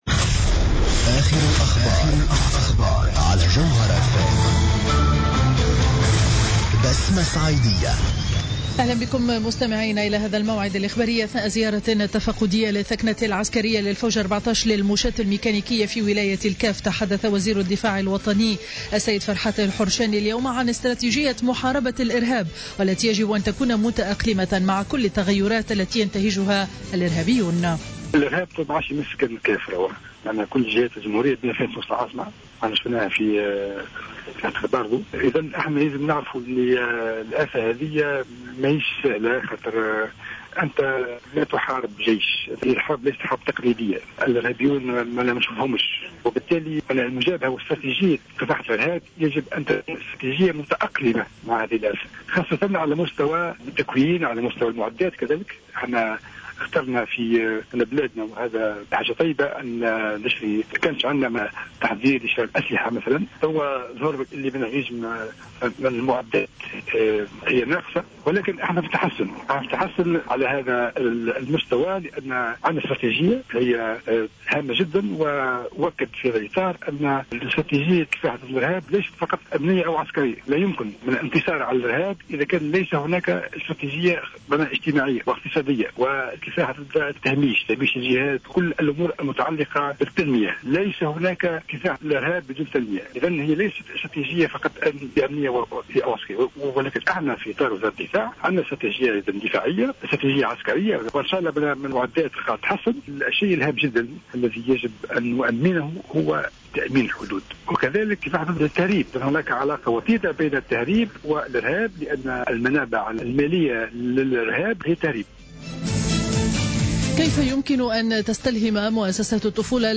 نشرة أخبار منتصف النهار ليوم الثلاثاء 14 أفريل 2015